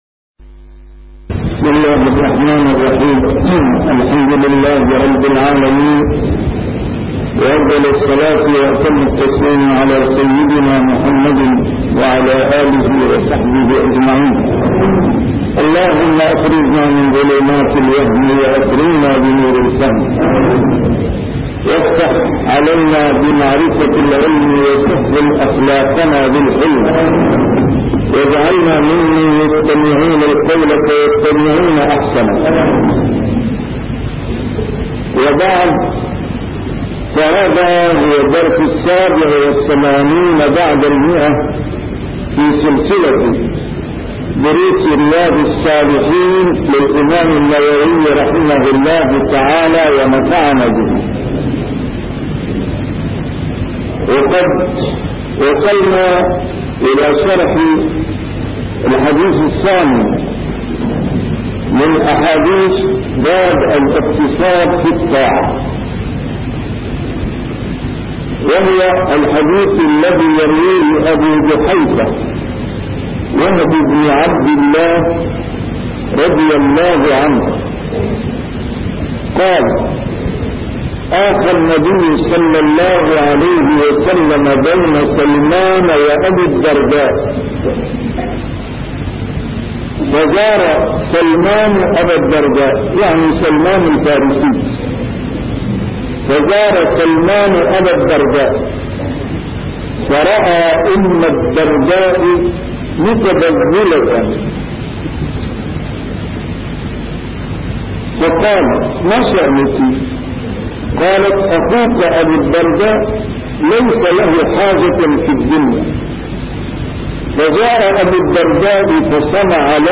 A MARTYR SCHOLAR: IMAM MUHAMMAD SAEED RAMADAN AL-BOUTI - الدروس العلمية - شرح كتاب رياض الصالحين - 187- شرح رياض الصالحين: الاقتصاد في العبادة